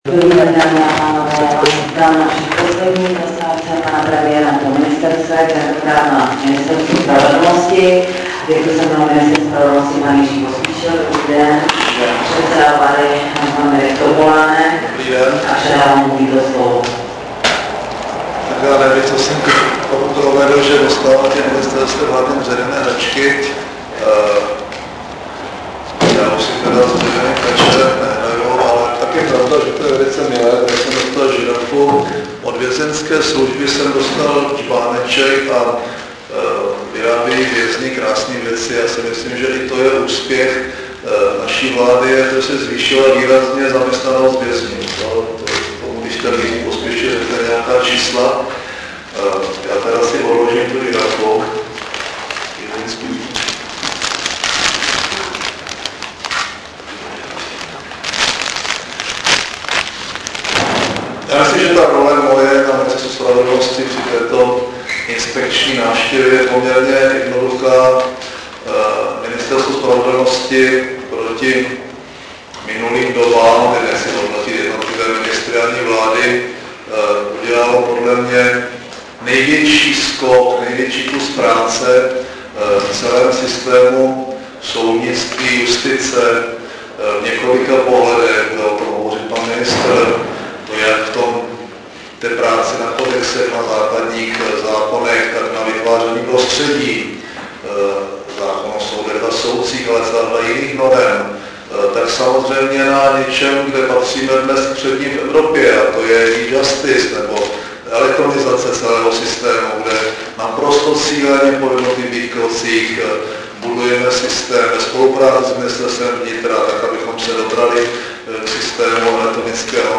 Předseda vlády M. Topolánek na tiskové konferenci po jednání s čelními peřdstaviteli Ministerstva spravedlnosti ČR vyjádřil uznání nad výsledky práce týmu ministra J. Pospíšila. Jako hlavní úkol ministerstva jmenoval dokončení příprav novely trestního řádu.